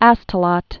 (ăstə-lŏt, -lăt)